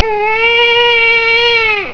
A mIRC newbie crying.
newbcry.wav